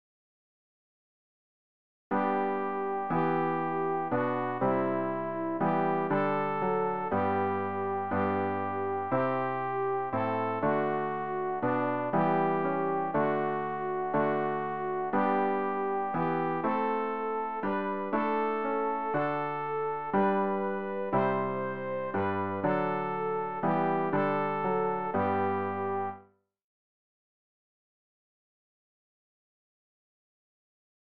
rg-748-amen-gott-vater-und-sohne-sopran.mp3